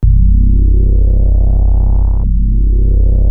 JUP 8 C2 9.wav